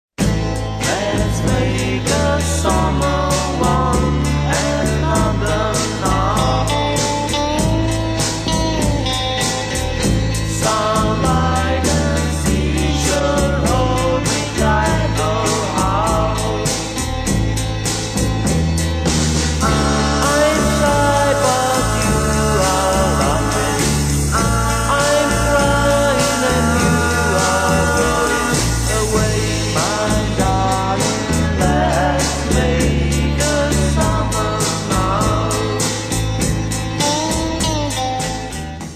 Jeden z milníků historie české a slovenské rockové hudby.